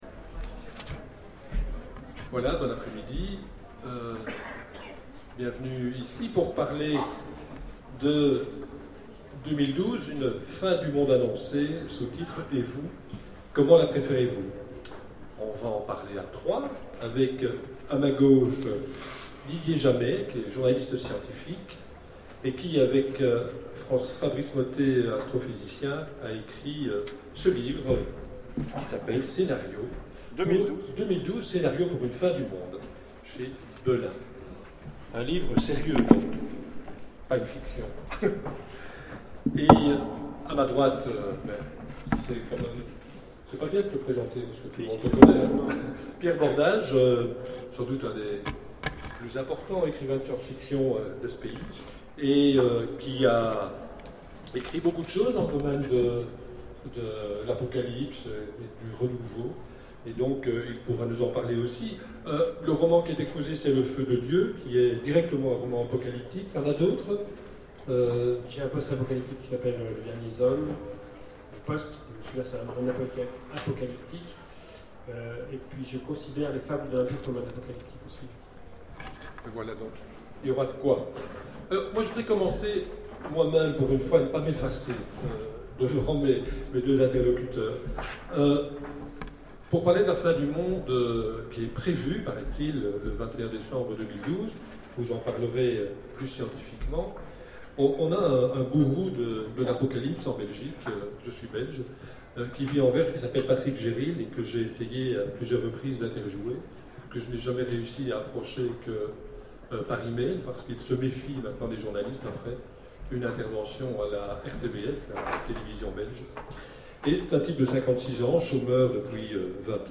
Imaginales 2012 : Conférence 2012, une fin du monde annoncée...